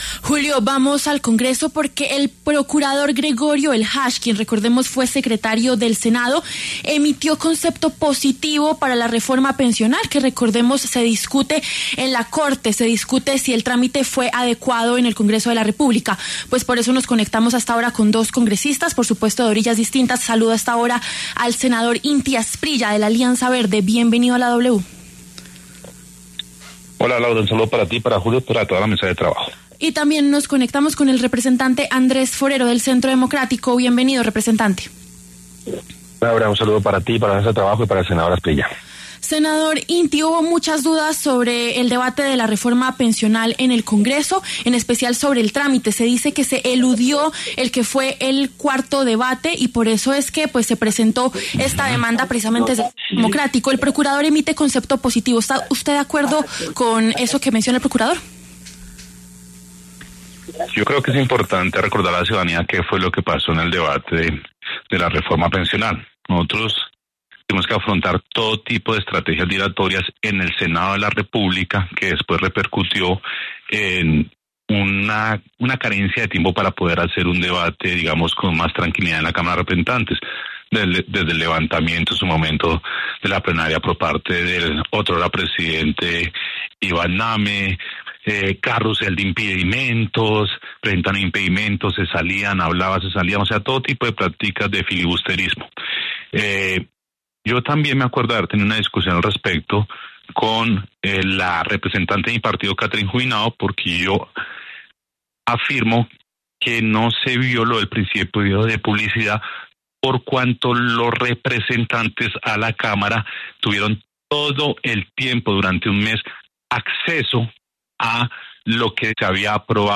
Debate congresistas: ¿Se equivocó el procurador Eljach tras avalar trámite de reforma pensional?
El senador Inti Asprilla, de Alianza Verde, y el representante Andrés Forero, del Centro Democrático, pasaron por los micrófonos de La W.